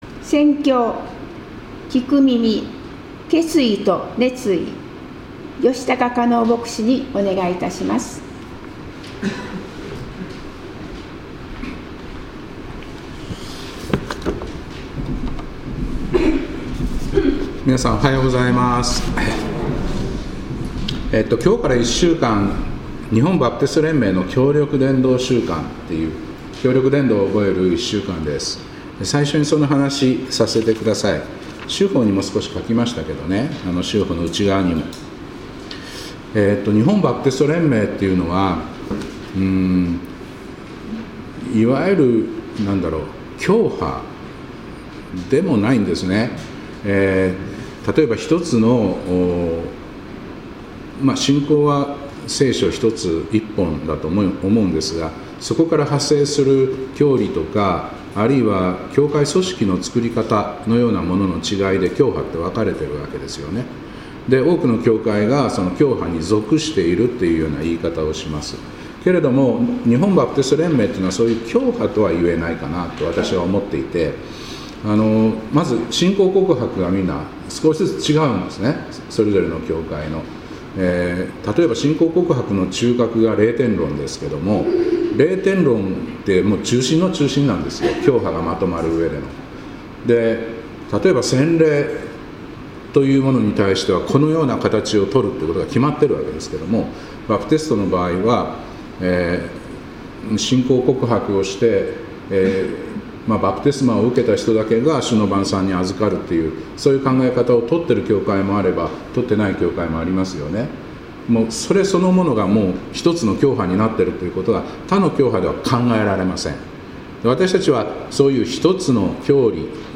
2026年1月25日礼拝「聞く耳・決意と熱意」